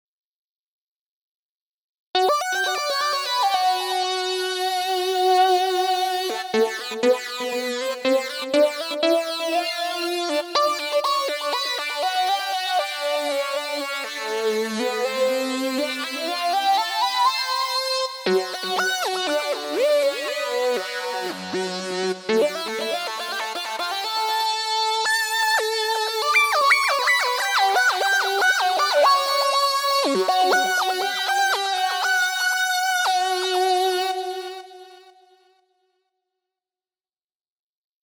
На днях приобрёл данную миди-клавиатуру, обнаружилось, что при быстром вращении колеса питча, высота меняется ступенчато, что-то типа рандомного арпеджио.